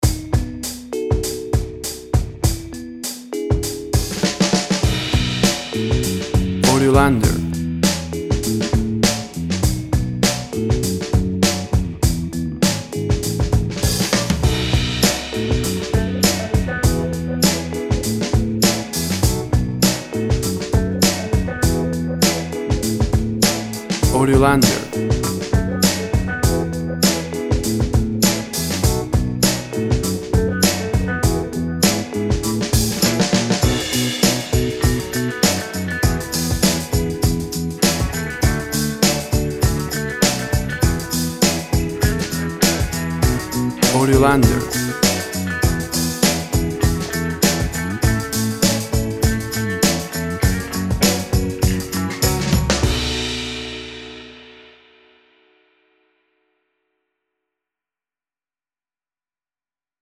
WAV Sample Rate 24-Bit Stereo, 44.1 kHz
Tempo (BPM) 100